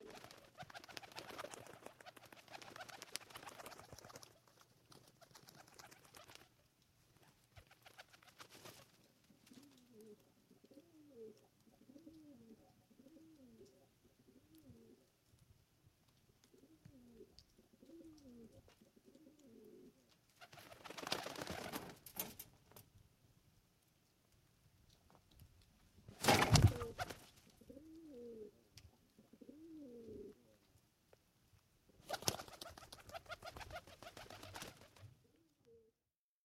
Шум крыльев голубей в голубятне